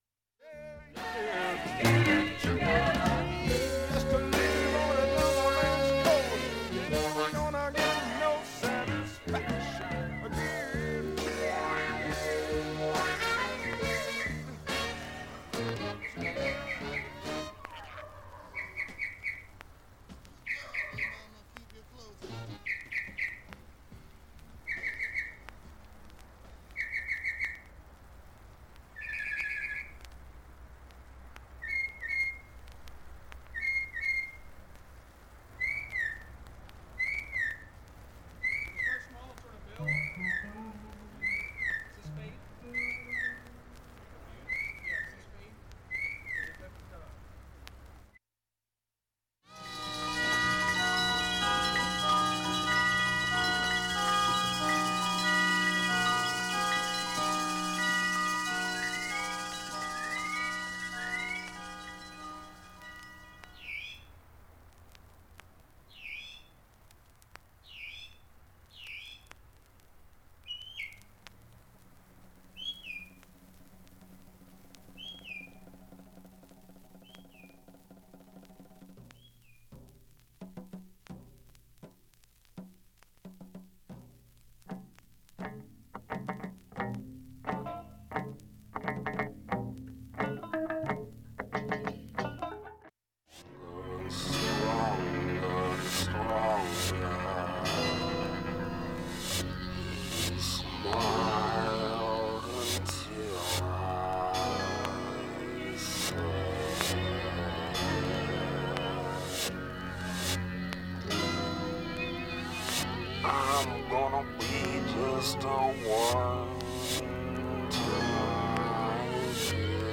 音質良好全曲試聴済み。
ドラム・ブレイクから始まる疾走系
インスト・ファンキー・ロック